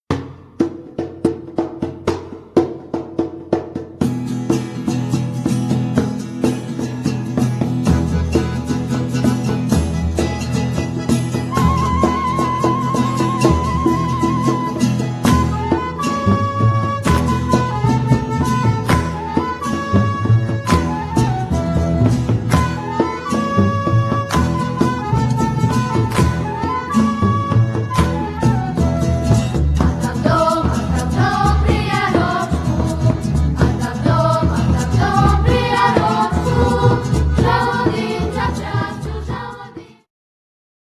gitara, ¶piew
flet poprzeczny
Djambe
akordeon
¶piew, przeszkadzajki
skrzypce
kontrabas